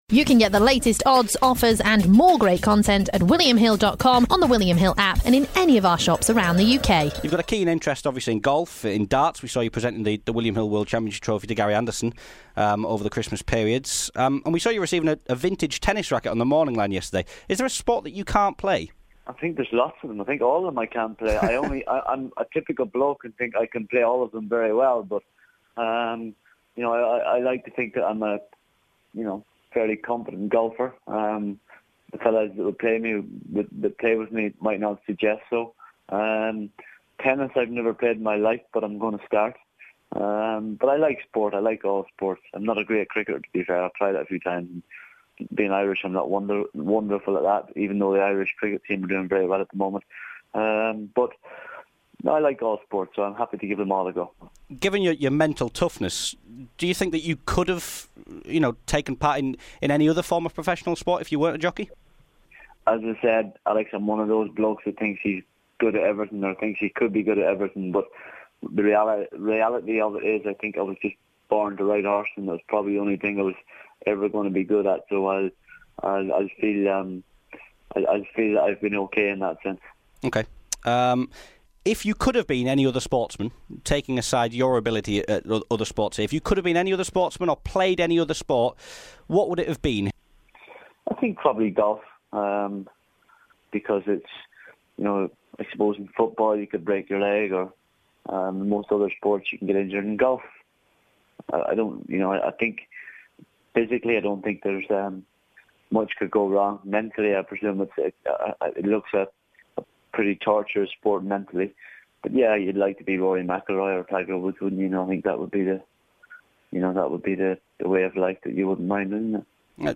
AP McCoy spoke to William Hill on Sunday morning following his retirement. We know AP has a keen interest in a number of different sports, but what sport would he rather play if he hadn't been a jockey?